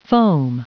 Prononciation du mot foam en anglais (fichier audio)
Prononciation du mot : foam